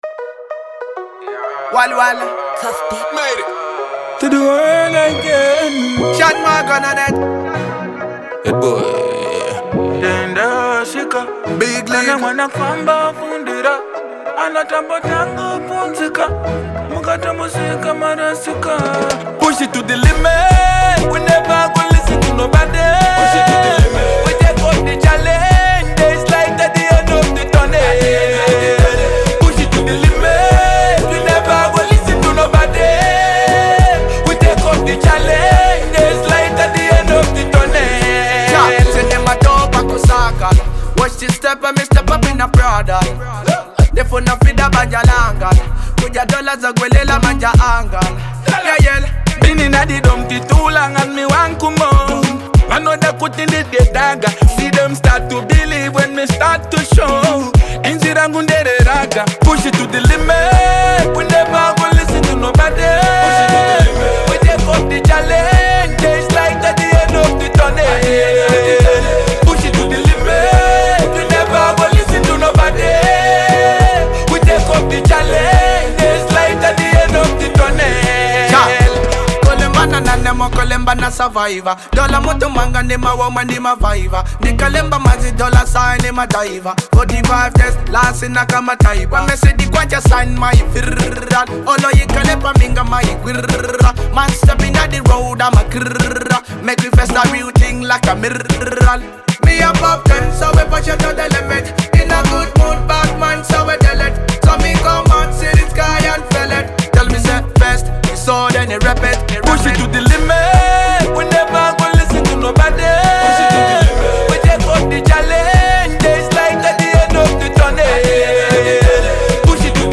Genre : Dancehall